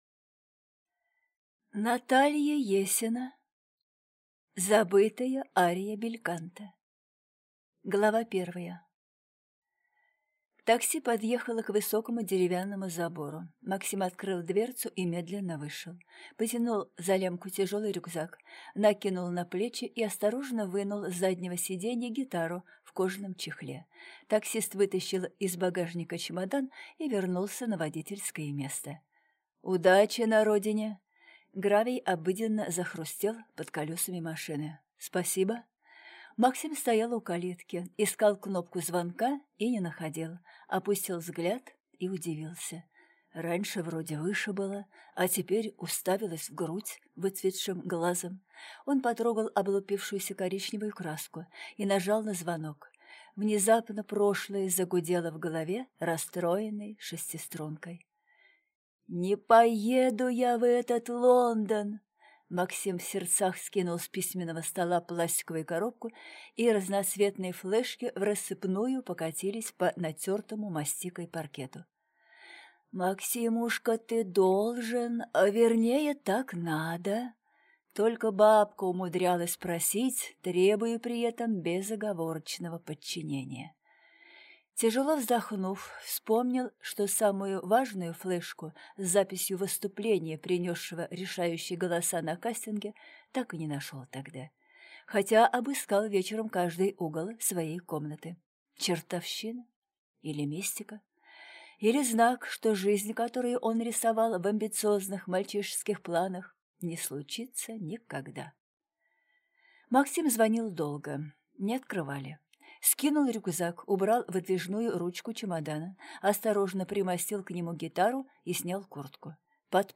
Аудиокнига Забытая ария бельканто | Библиотека аудиокниг